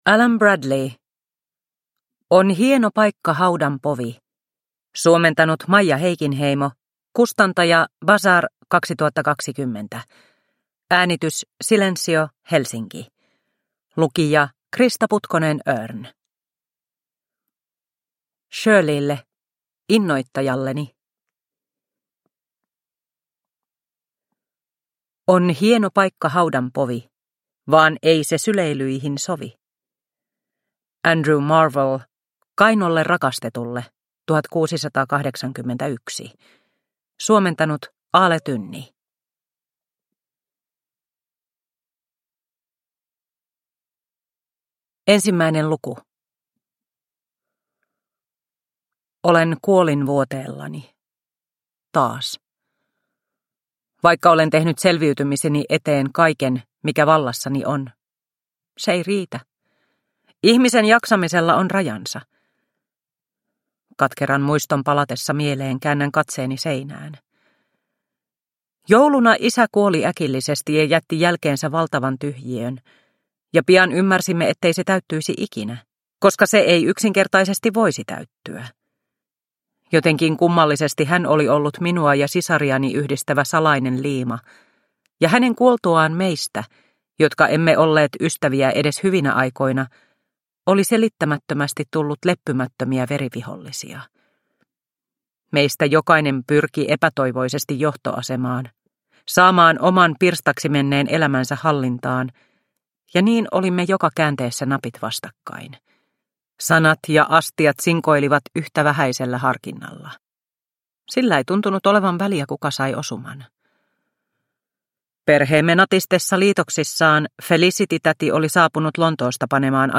On hieno paikka haudan povi – Ljudbok – Laddas ner